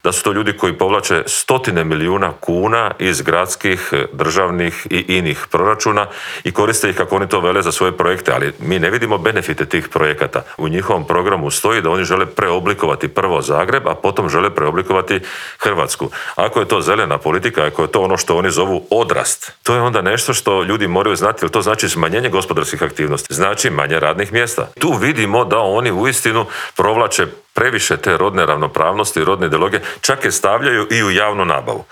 " To nije nikakva taktika, već želja mog tima da pokažemo o kome se radi", kaže nam u Intervjuu tjedna Media servisa Škoro.